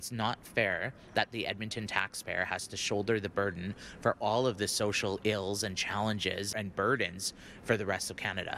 Ward papastew councillor Michael Janz says this isn’t fair for Edmonton taxpayers:
michael-janz-on-edmonton-taxpaying.mp3